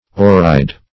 Oreide \O"re*ide\, n.